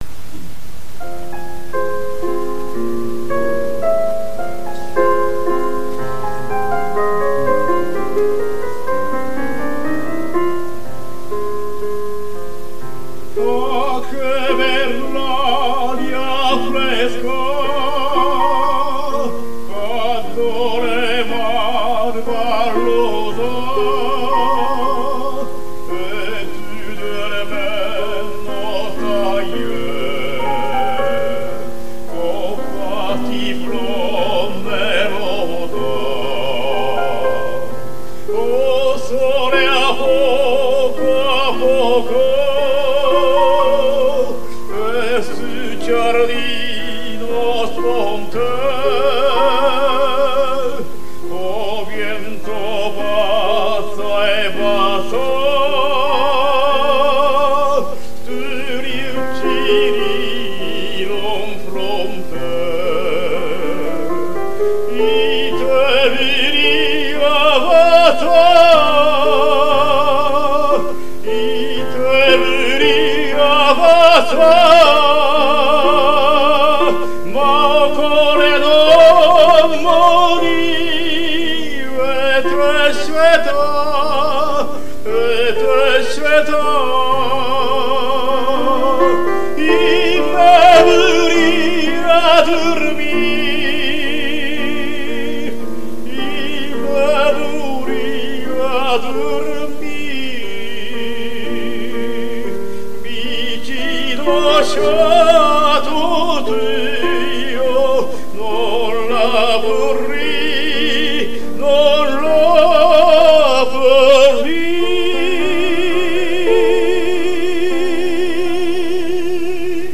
バリトン